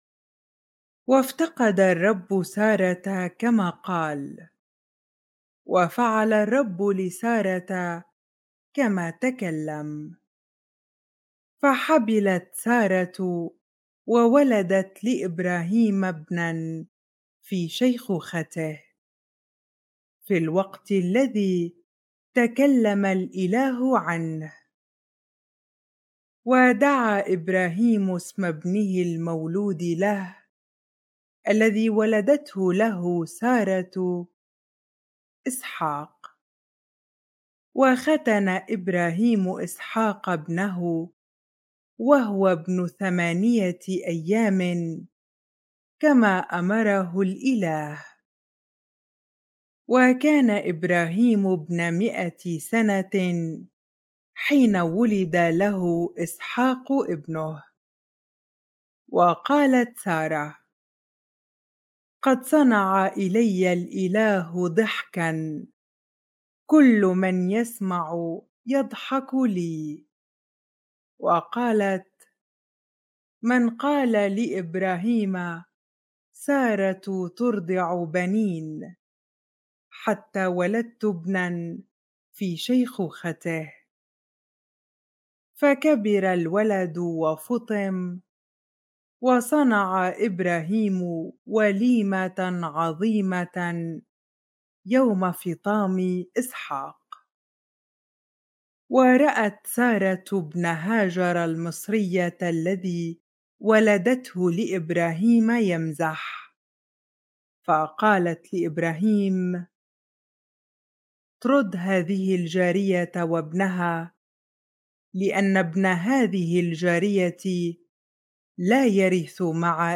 bible-reading-genesis 21 ar